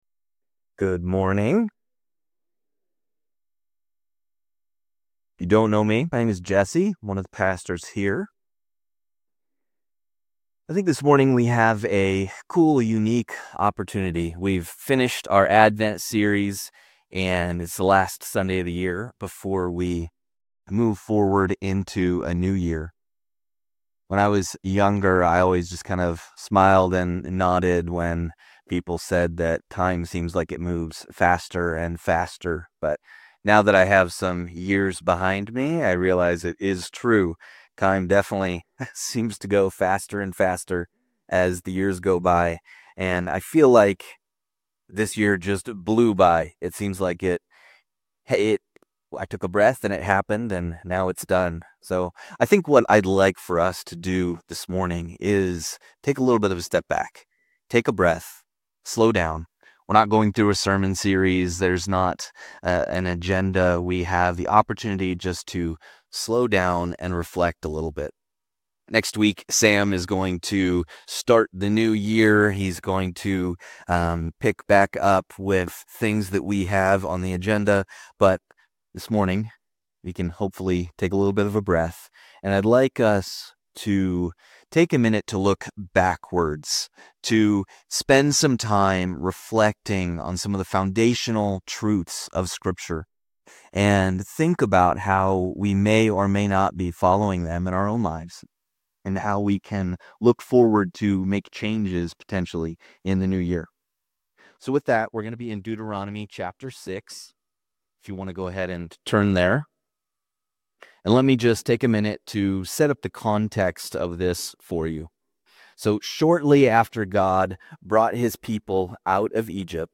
Drawing from Deuteronomy 6, this year-end message addresses declining faith among younger generations and offers practical ways to leave a lasting spiritual legacy. Discover why intergenerational discipleship matters and how you can pour into younger believers in your community.